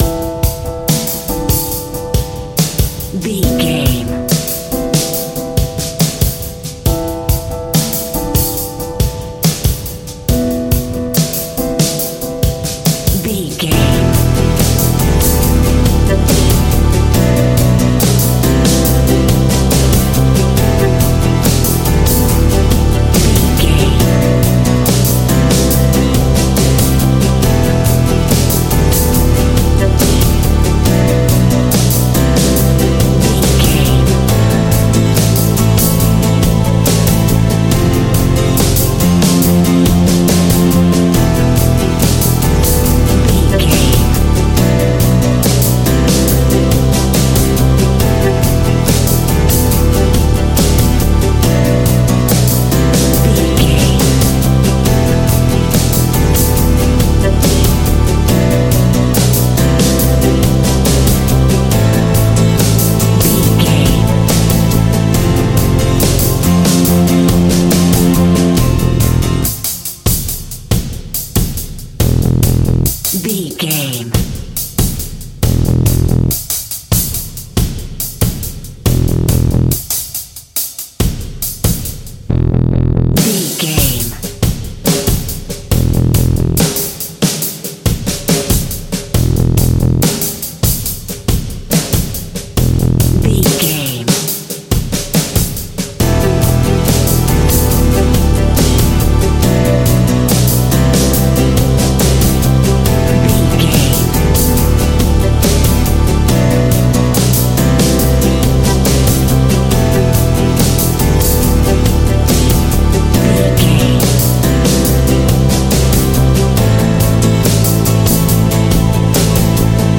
Aeolian/Minor
groovy
powerful
organ
drums
bass guitar
electric guitar
piano